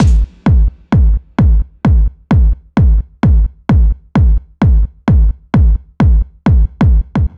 Sf Bd Loop.wav